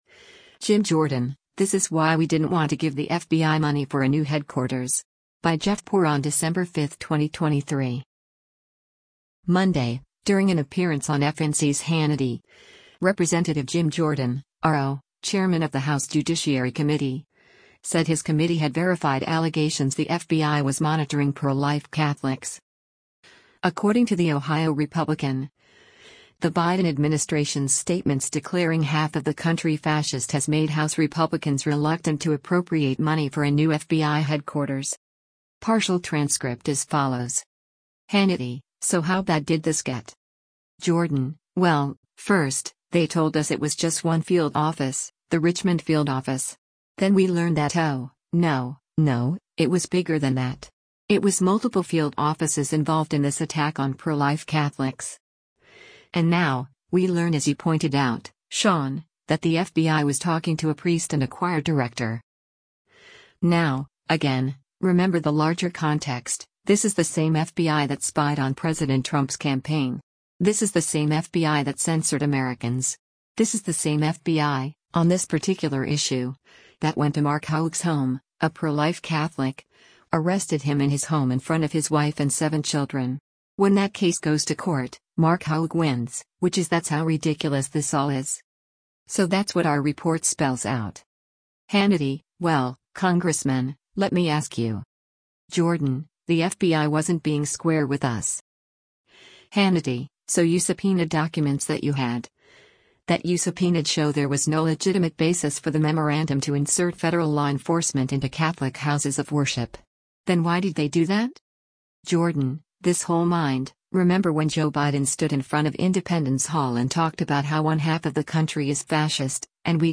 Monday, during an appearance on FNC’s “Hannity,” Rep. Jim Jordan (R-OH), chairman of the House Judiciary Committee, said his committee had verified allegations the FBI was monitoring pro-life Catholics.